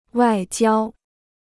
外交 (wài jiāo) Free Chinese Dictionary
外交 (wài jiāo): diplomacy; diplomatic.